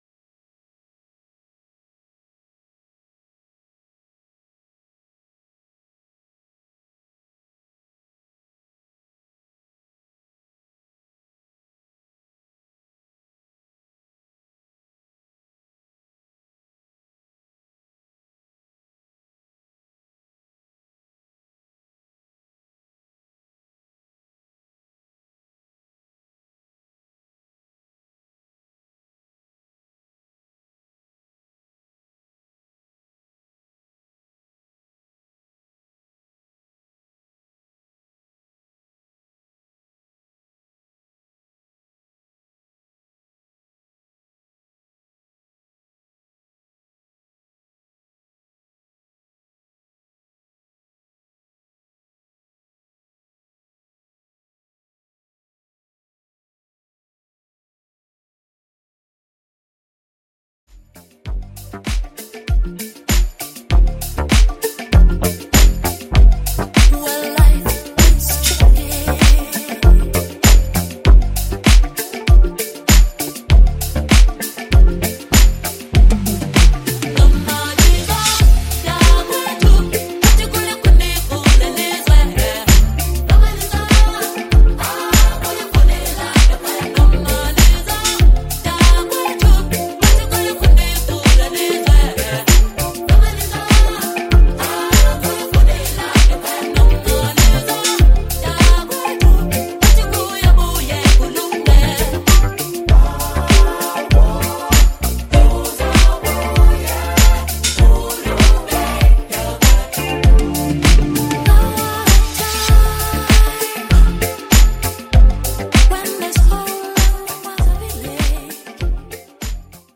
superior disco edits and reworks